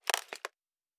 Plastic Foley 02.wav